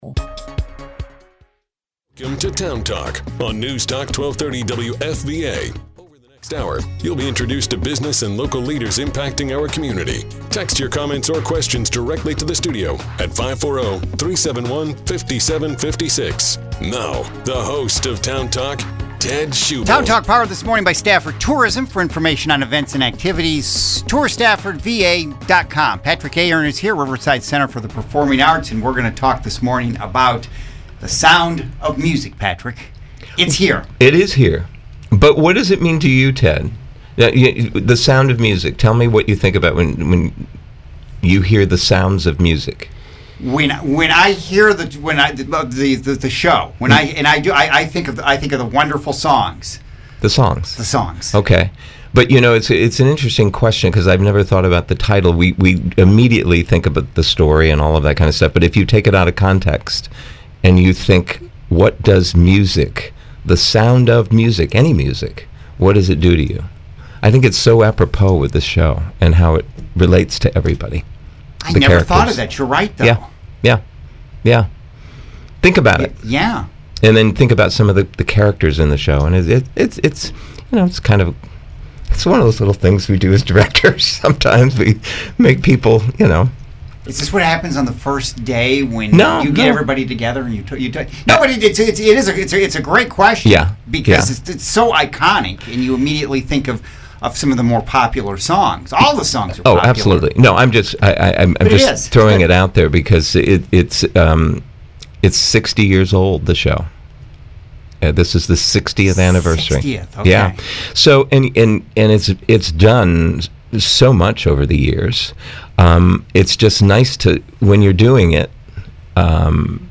Heard every weekday morning from 8-9am on NewsTalk 1230, WFVA